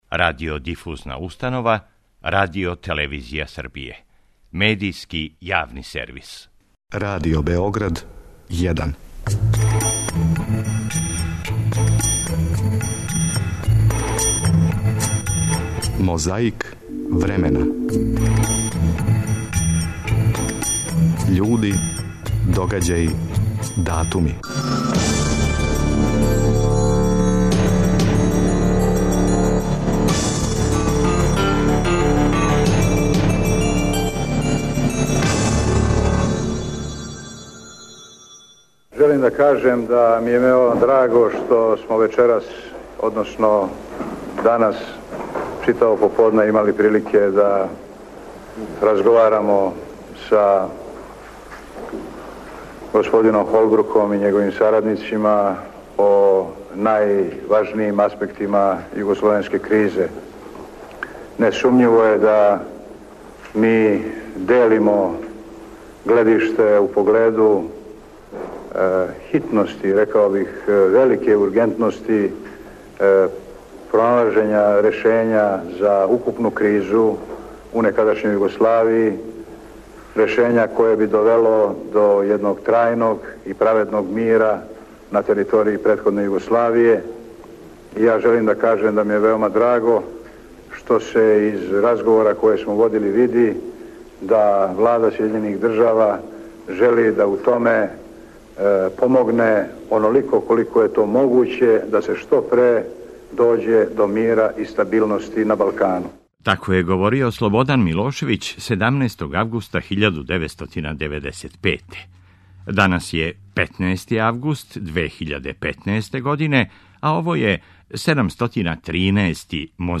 Подсећа на прошлост (културну, историјску, политичку, спортску и сваку другу) уз помоћ материјала из Тонског архива, Документације и библиотеке Радио Београда.
16. августа 1997. тадашња председница Републике Српске, Биљана Плавшић, обратила се јавности путем телевизије.